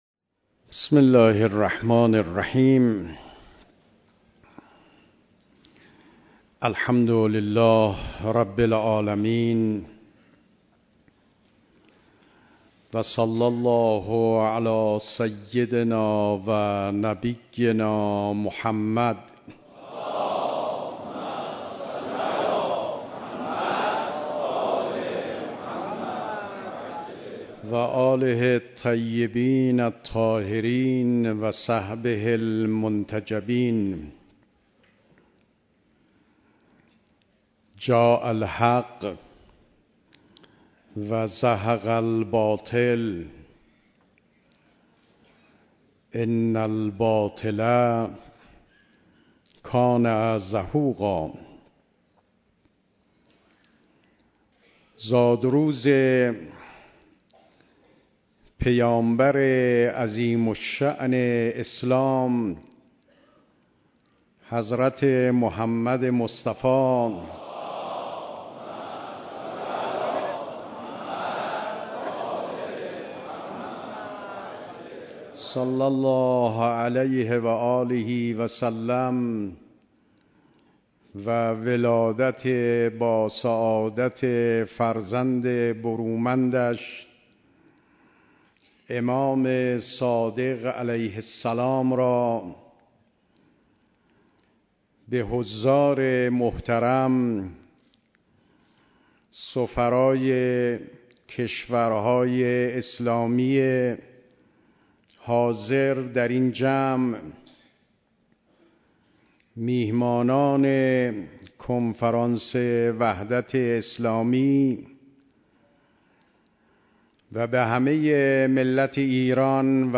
صوت / سخنرانی ریاست محترم جمهور جناب آقای روحانی